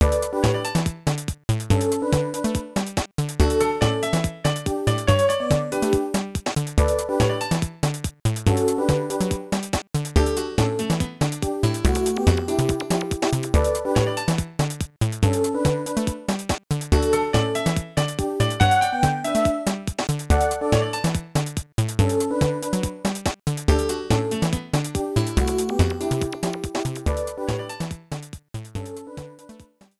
Ripped with Nitro Studio 2
Cropped to 30 seconds, fade out added